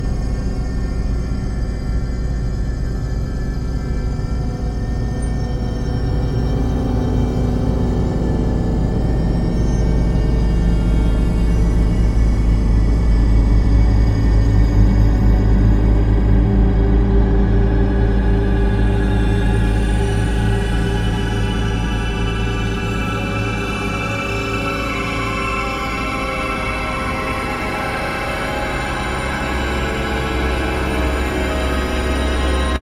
For Techno it can still be useful though, I've generated a bunch of cool space soundtracks with Udio which I'm sometimes slicing up and processing with other tools to create nice dark athmospheres. I've attached an unprocessed one to the post.